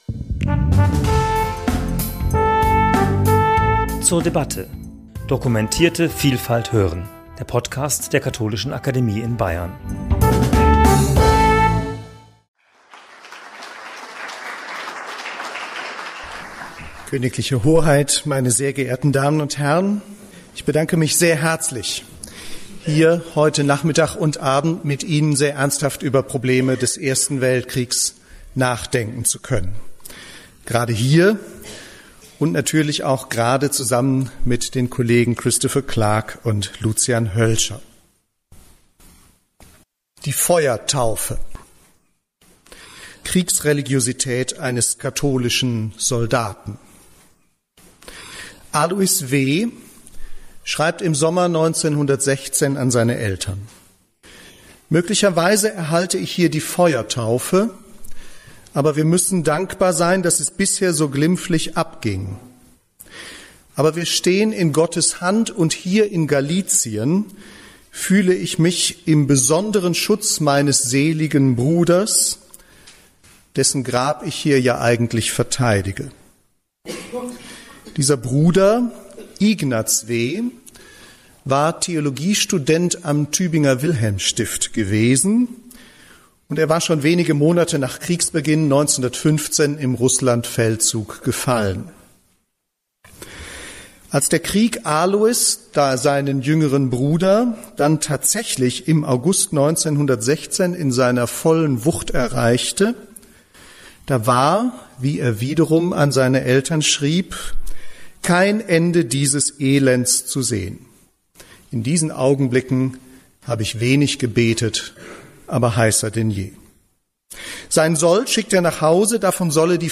in der Katholischen Akademie in Bayern